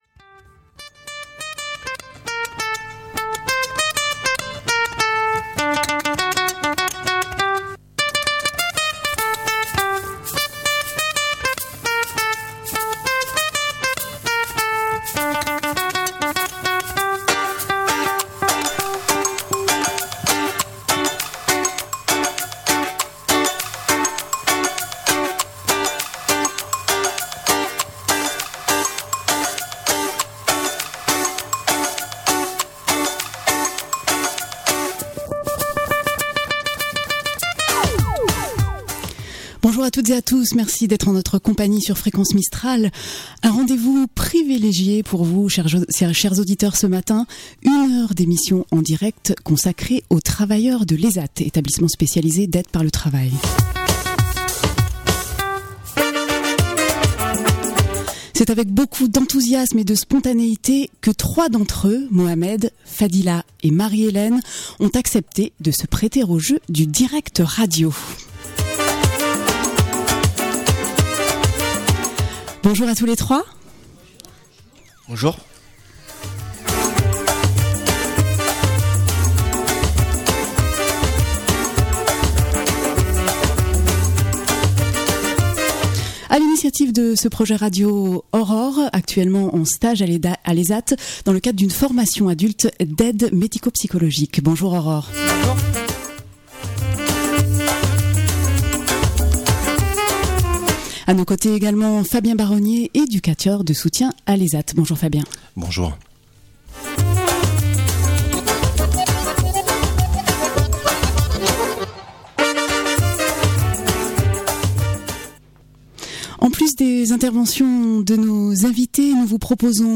Trois travailleurs handicapés étaient dans nos studios et se sont prêté au jeu du direct avec beaucoup d'enthousiasme.
L'émission propose aussi un voyage sonore dans leur quotidien en atelier de production avec ceux qui les accompagnent, moniteurs et éducateurs de la structure.